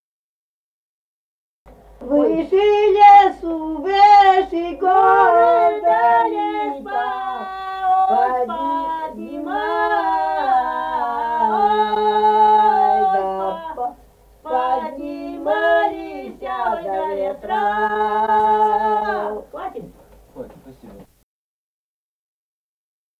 Республика Казахстан, Восточно-Казахстанская обл., Катон-Карагайский р-н, с. Коробиха, июль 1978. И 1775-18б (повторная запись первой строфы).